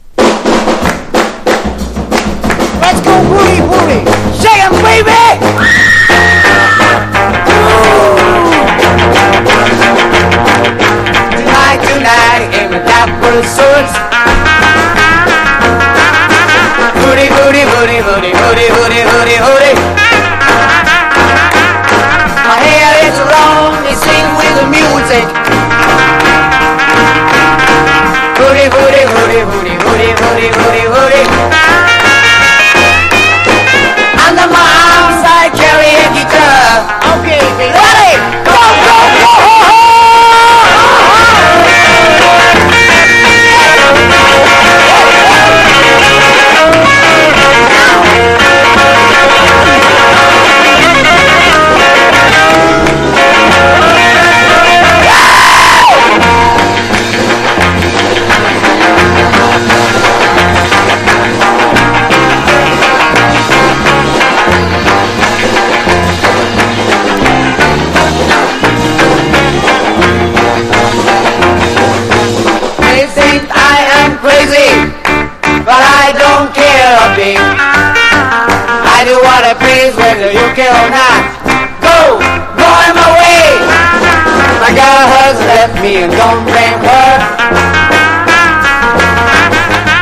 60-80’S ROCK